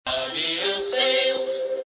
из рекламы
голосовые